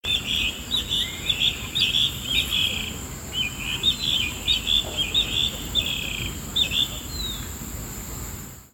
Plumbeous Rail (Pardirallus sanguinolentus)
Location or protected area: Reserva Ecológica Ciudad Universitaria - Costanera Norte (RECU-CN)
Condition: Wild
Certainty: Recorded vocal
GallinetaComun.mp3